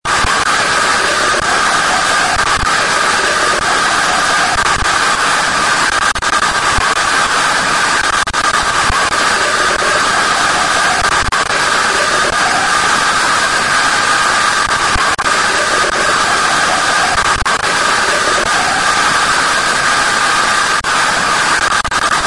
Sound Effects
Scary Static Noise